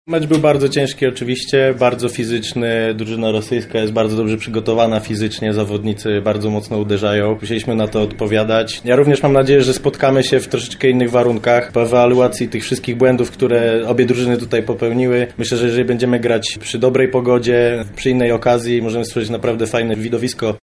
Graliśmy z naprawdę trudnym rywalem – podkreśla skrzydłowy biało-czerwonych